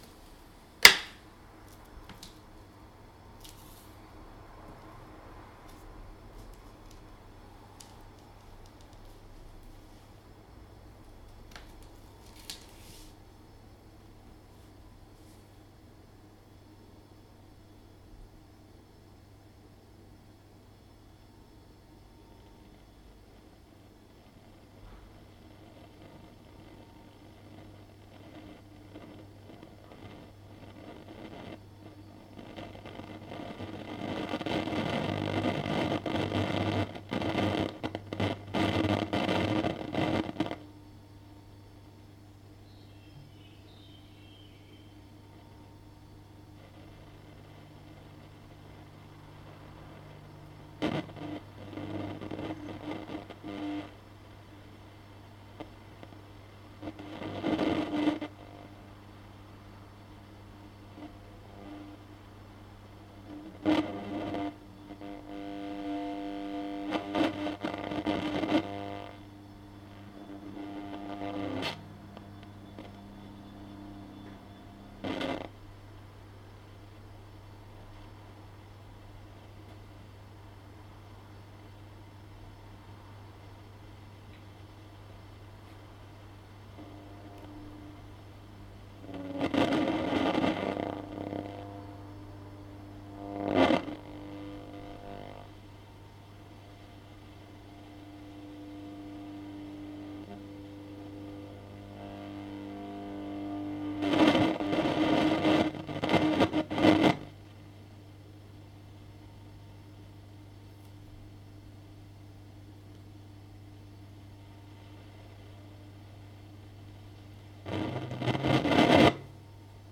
Bizarrement, il s'est mis à faire des sons très étranges que vous pourrez entendre ici
Buzz.MP3
Comme vous l'entendrez, c'est assez aléatoire comme son, il s'arrête puis reprend, est plus ou moins fort ...
Seule chose, les craquements semblent sensiblement plus importants si on tape (légèrement bien sûr) sur l'ampli (ce que je fais à la fin de mon enregistrement), et peu importe qu'on tape sur les parties en bois ou en métal (je me suis posé la question d'un problème de masse).